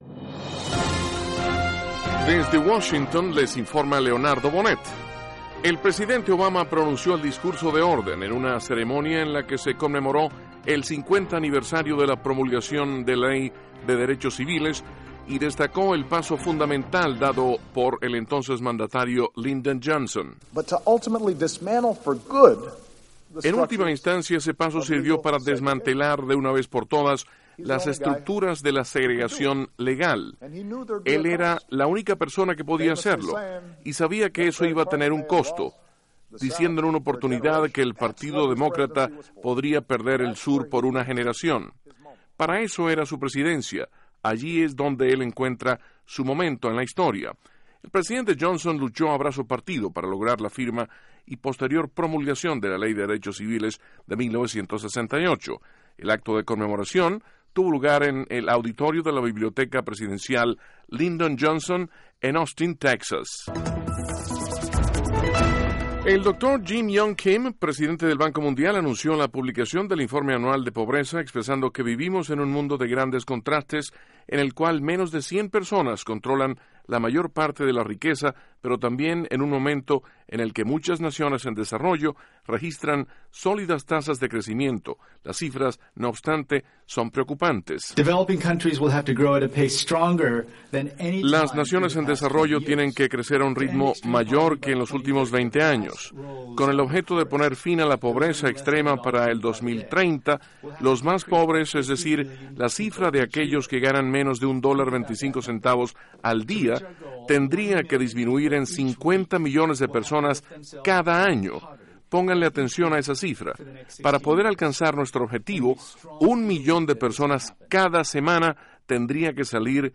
Duración: 3:20 Contenido: 1.- El presidente Obama conmemora el 50 aniversario de la promulgación de la Ley de Derechos Civiles. (Sonido Obama) 2.- Jim Yong Kim, presidente del Banco Mundial, afirma que menos de 100 personas controlan la riqueza del mundo. (Sonido – Kim) 3.- El comediante Stephen Colbert sucederá a David Letterman como anfitrión del programa “Late Night”.